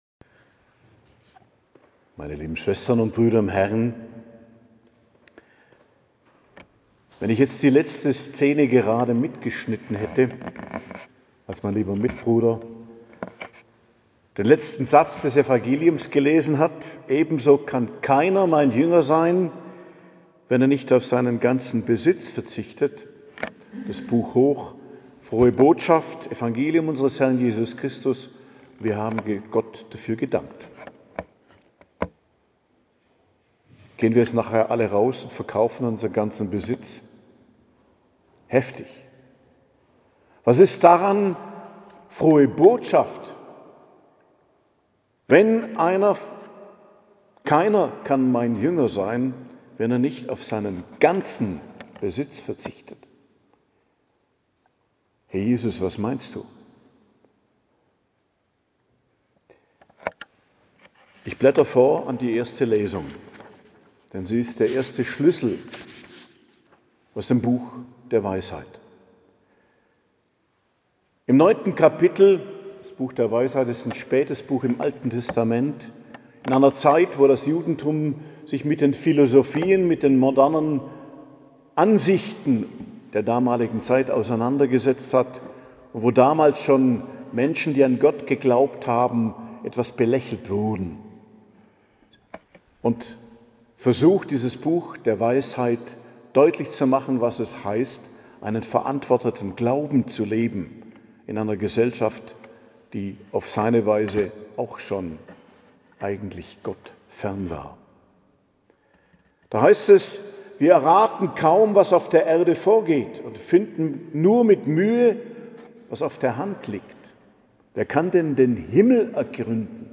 Predigt zum 23. Sonntag i.J., 7.09.2025 ~ Geistliches Zentrum Kloster Heiligkreuztal Podcast